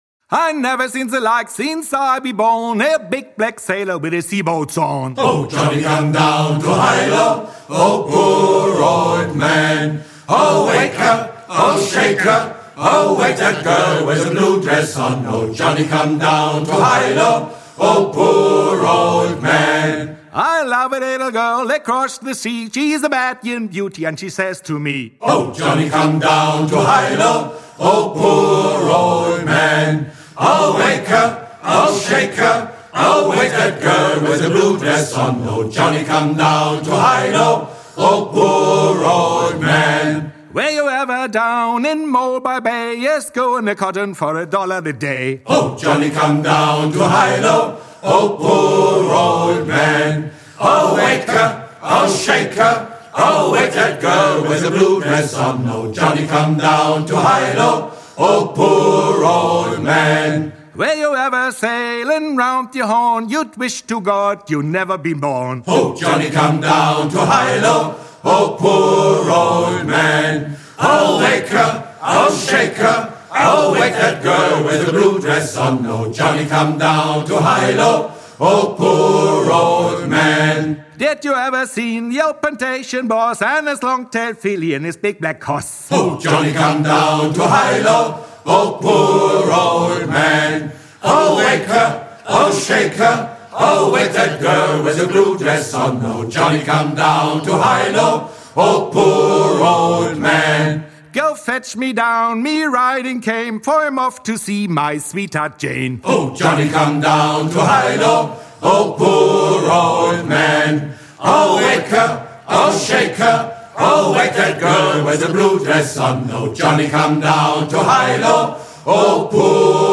Musik: trad.